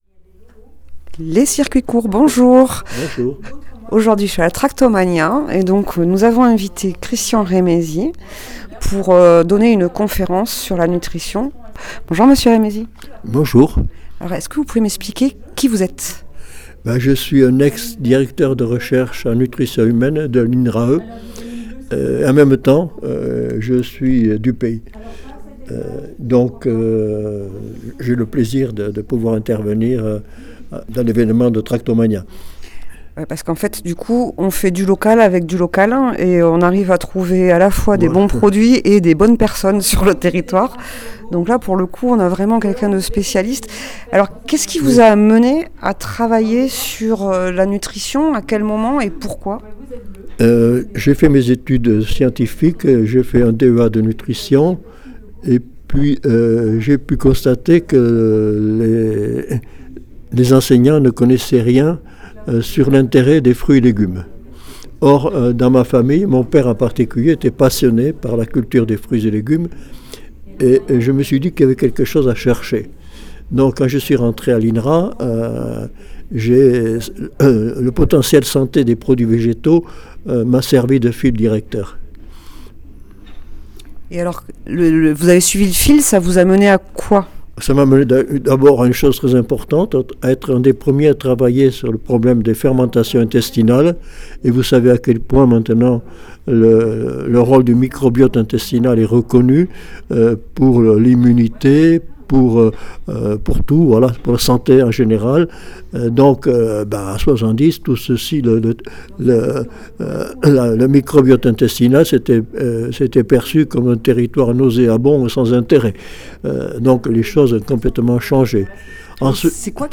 À l’occasion de Tractomania, le pays Midi-Quercy et le Civam Semailles organisaient une journée autour du Projet Alimentaire Territorial, samedi aux Récollets afin d’apporter un coup de projecteur sur les enjeux et démarches alimentaires locales.